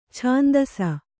♪ chāndasa